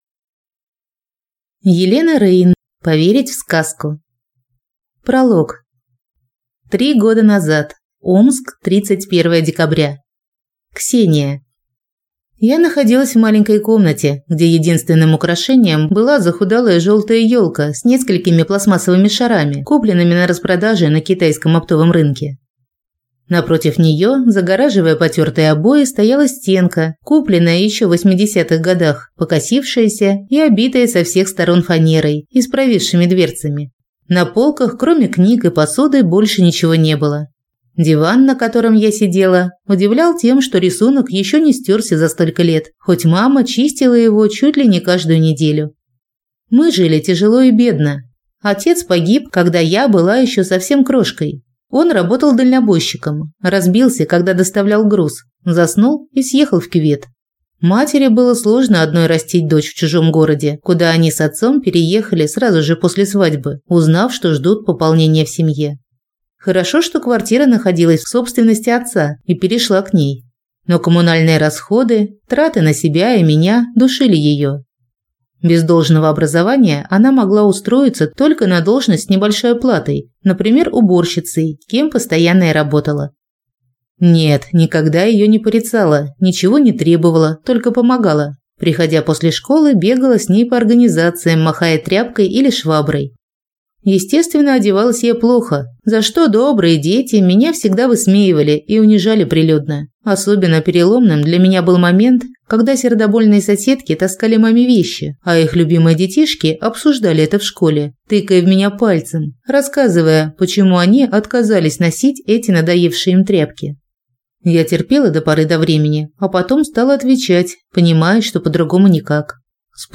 Аудиокнига Поверить в сказку | Библиотека аудиокниг